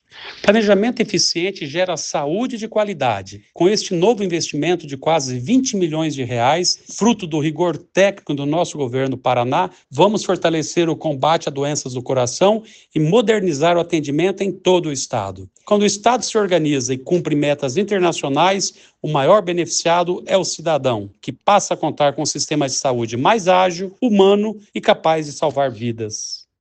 Sonora do secretário do Planejamento, Ulisses Maia, sobre o repasse do Banco Mundial para a Secretaria da Saúde
SONORA ULISSES MAIA - BANCO MUNDIAL.mp3